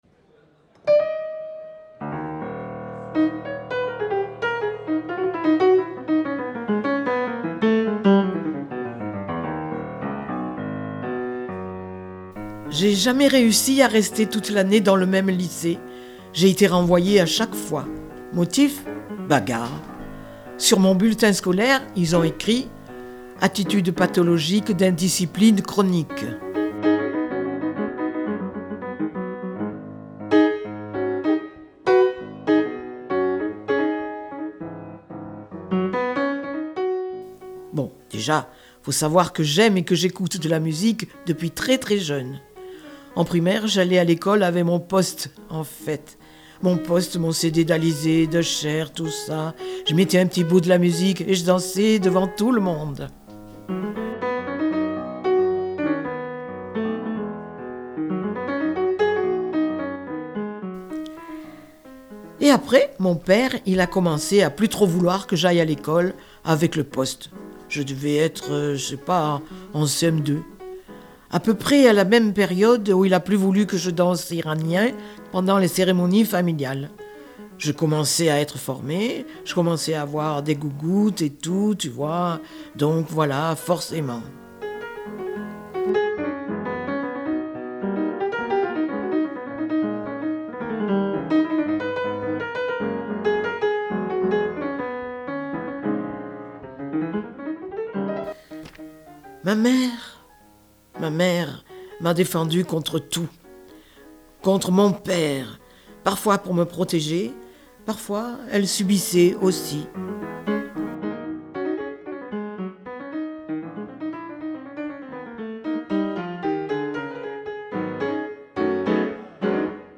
lu par